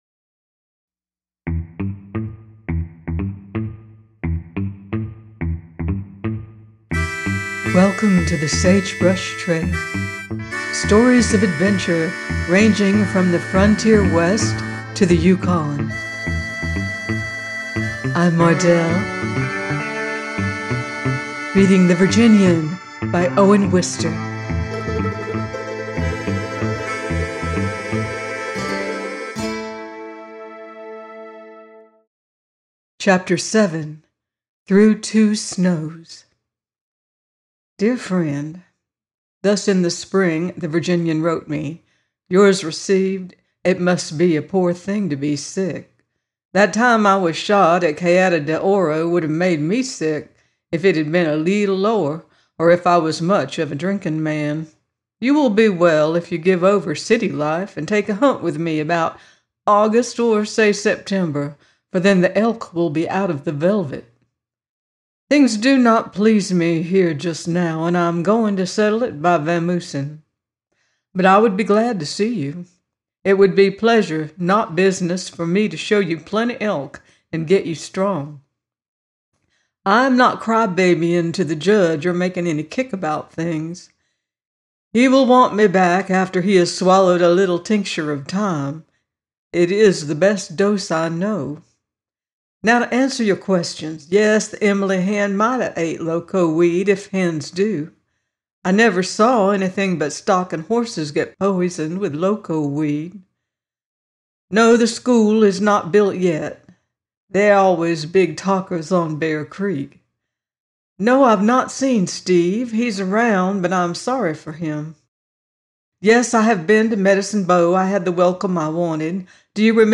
The Virginian 07 - by Owen Wister - audiobook